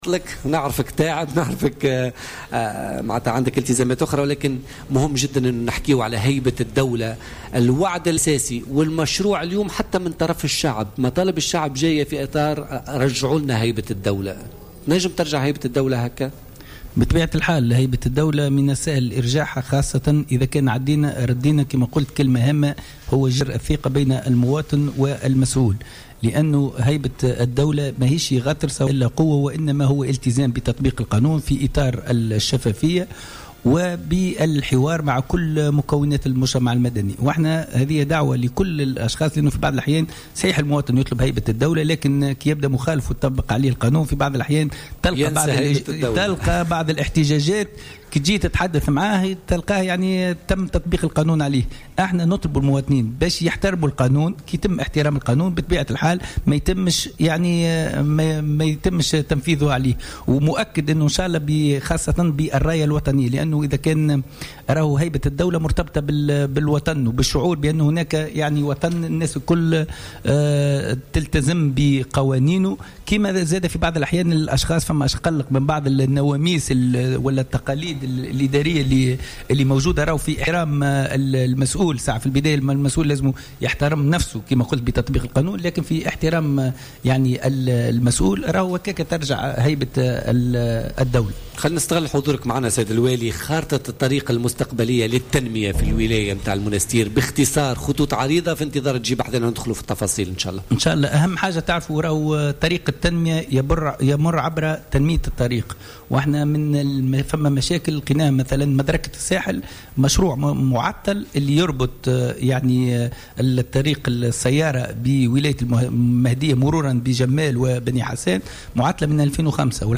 أكد والي المنستير عادل الخبثاني ضيف بوليتيكا اليوم الأربعاء 6 أفريل 2016 أن هيبة الدولة تعود بإعادة الثقة بين المواطن و المسؤول .